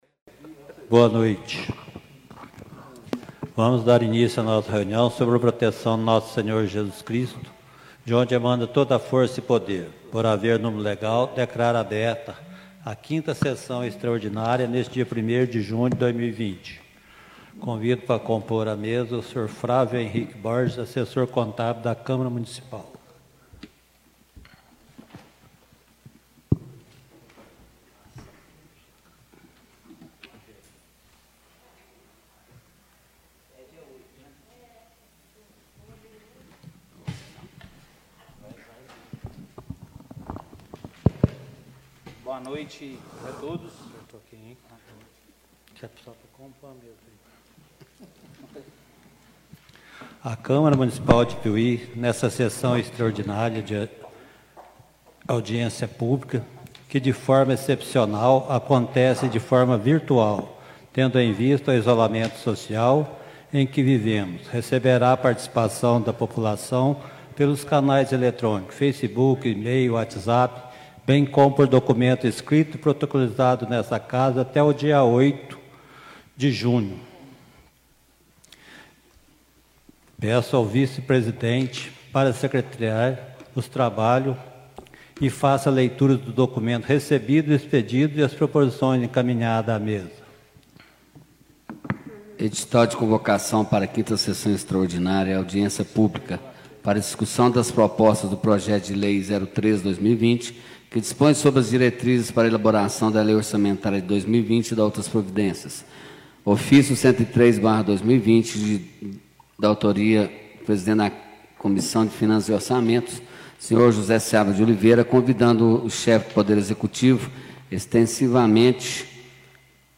5a_sessao_extraordinaria_-_2020.mp3